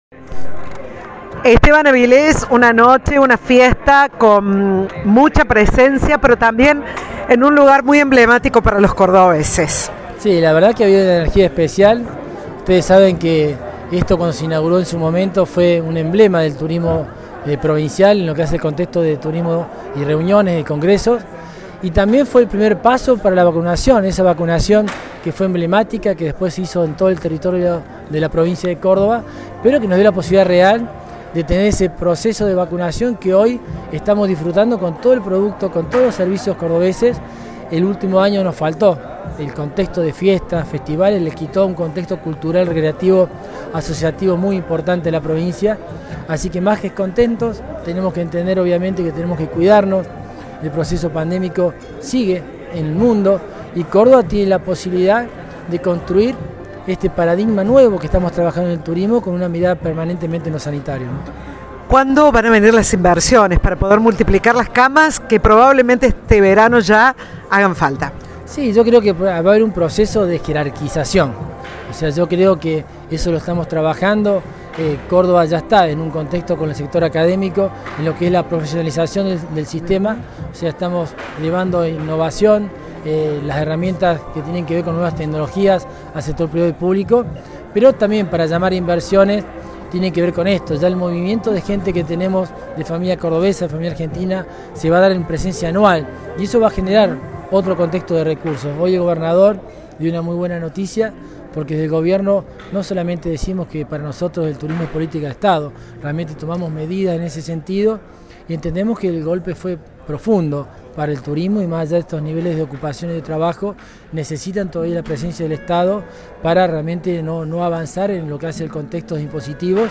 Audio. Esteban Avilés, titular de la Agencia Córdoba Turismo.
En un acto del que participaron diferentes representantes del sector turístico, la Provincia de Córdoba lanzó la campaña publicitaria para la temporada de verano 2021-2022.